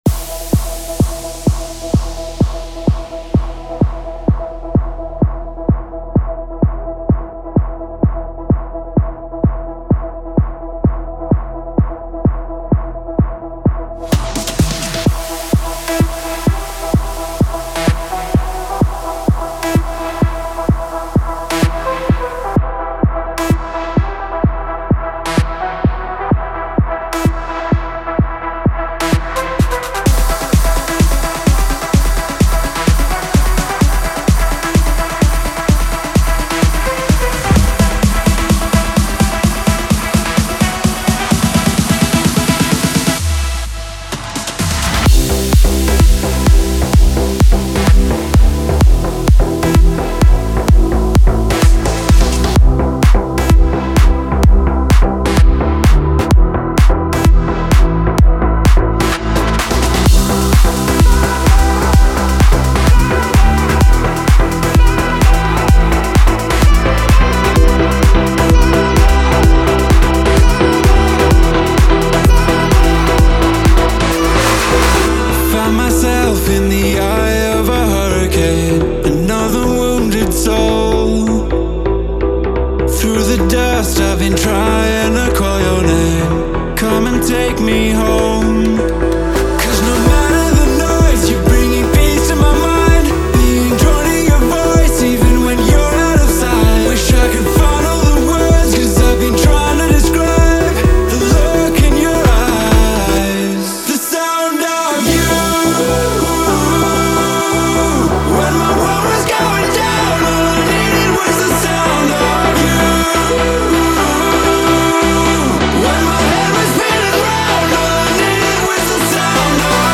Электронная